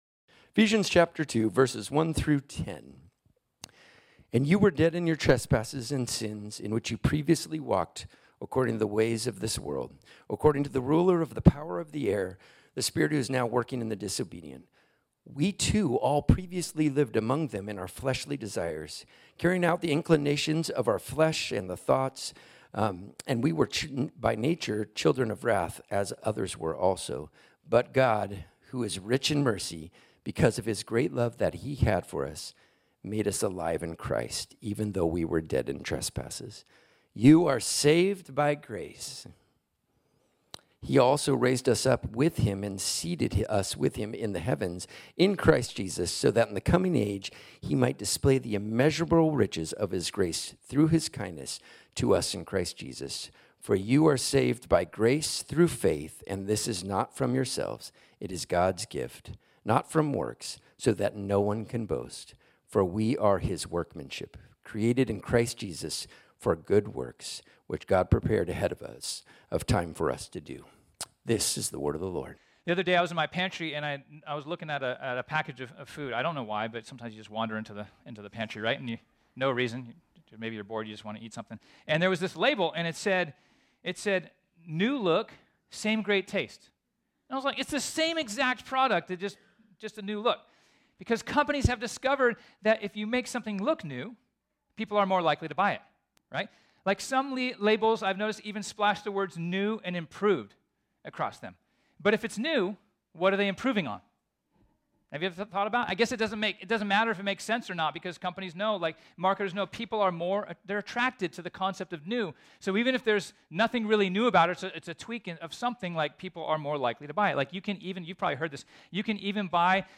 This sermon was originally preached on Sunday, September 24, 2023.